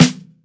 • Fluffy Snare Single Hit B Key 114.wav
Royality free snare drum sound tuned to the B note. Loudest frequency: 1889Hz
fluffy-snare-single-hit-b-key-114-E0p.wav